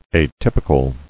[a·typ·i·cal]